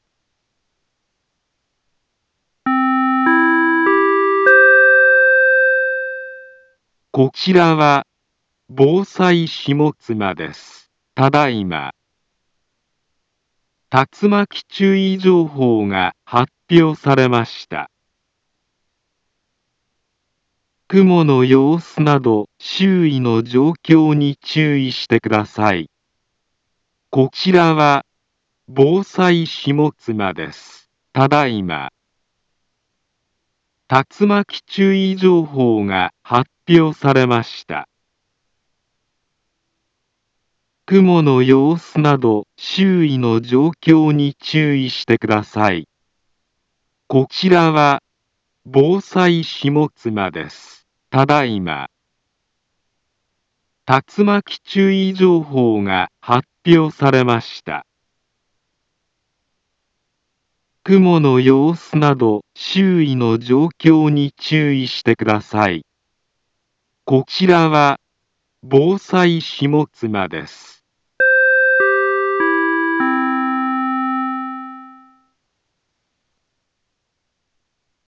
Back Home Ｊアラート情報 音声放送 再生 災害情報 カテゴリ：J-ALERT 登録日時：2025-09-05 15:05:14 インフォメーション：【目撃情報あり】茨城県北部で竜巻などの激しい突風が発生したとみられます。茨城県北部、南部は、竜巻などの激しい突風が発生するおそれが非常に高まっています。